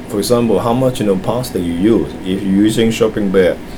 S1 = Taiwanese female S2 = Hong Kong male Context: S2 is talking about human wastage. S2 : ... for example how much you know plastic you use. if you using shopping bag Intended Words : plastic Heard as : past Discussion : There is no [l] in the word, and no final [k] ; in fact plastic is pronounced as [pɑ:stə] .